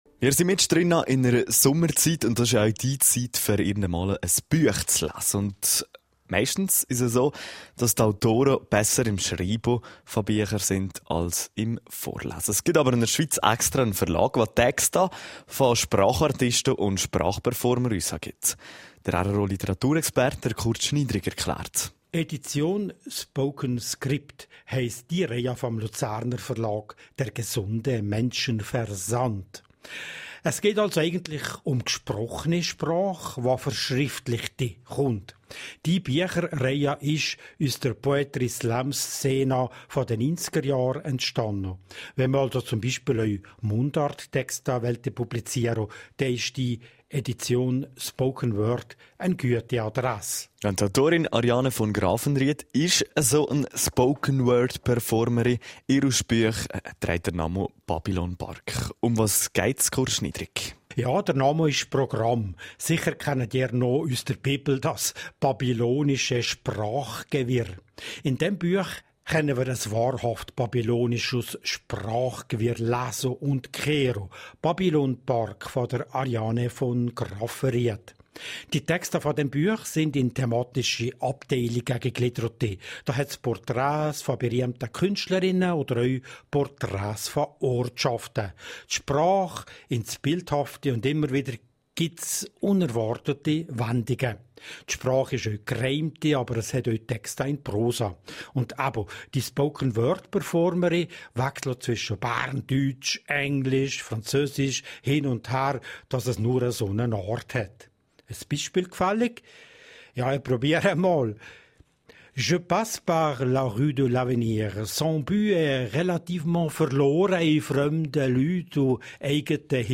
Spoken-Word-Performer